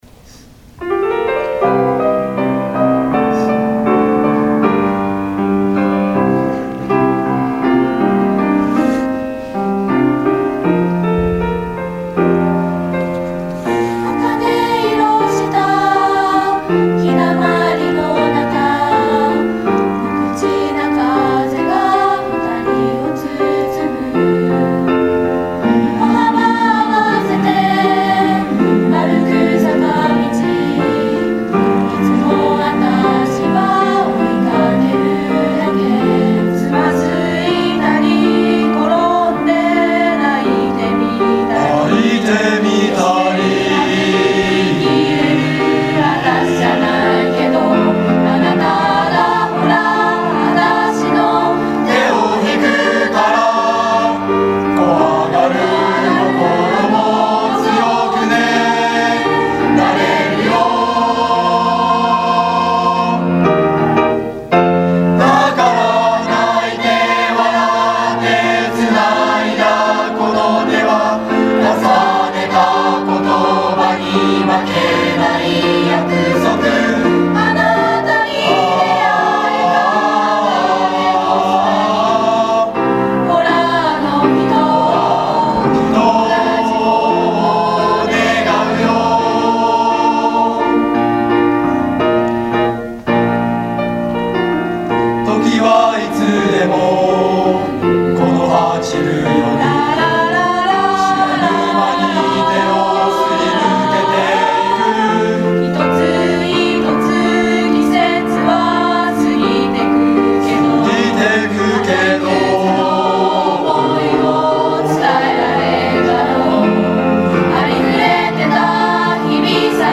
～校内合唱コンクールが行われました～
第2学期の主要な行事の一つである校内合唱コンクールが11月11日（火）午前中に，本学院メインアリーナを会場に開催されました。